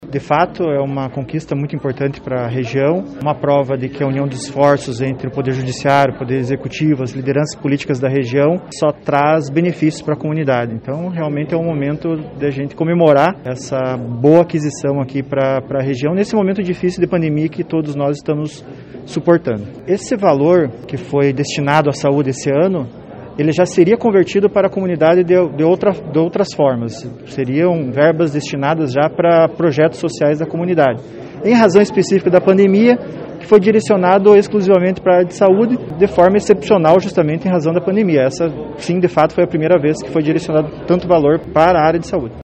RESPIRADORES-03-JUIZ-EMERSON-LUCIANO-PRADO-SPAK-FALA-DO-VALOR-INVESTIDO-EM-PROL-DA-SAÚDE-DA-POPULAÇÃO.mp3